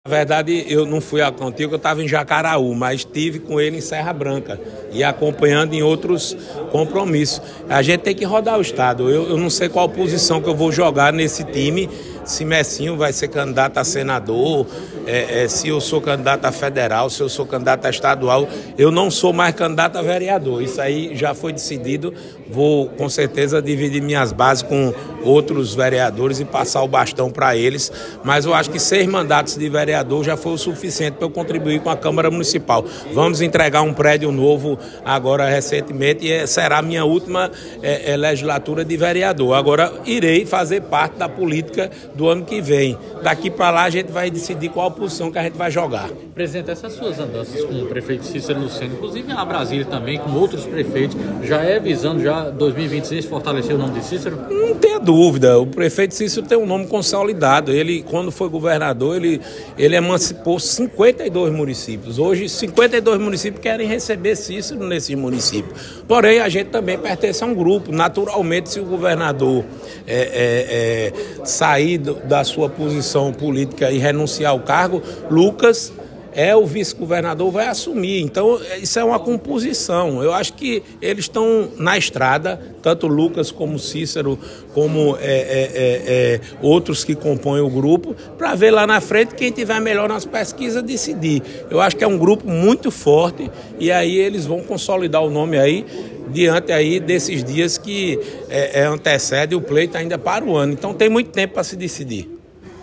Abaixo a fala do presidente da CMJP, Dinho.